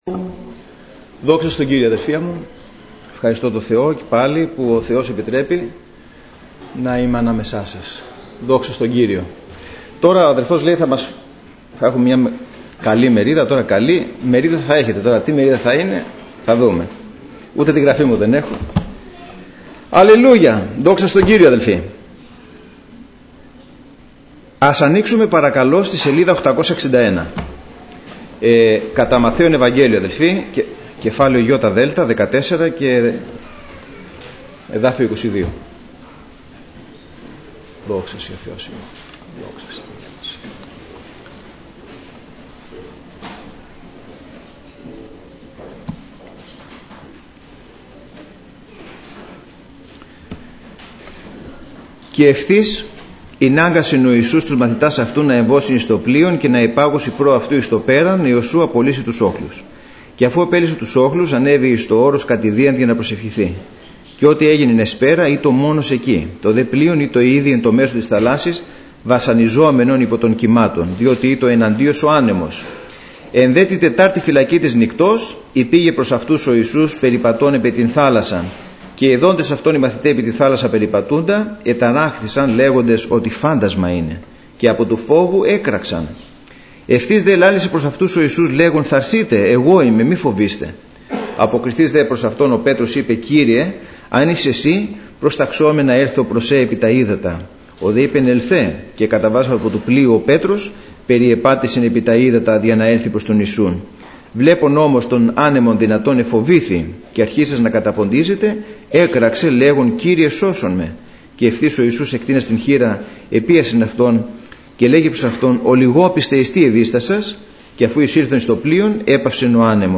Σειρά: Κηρύγματα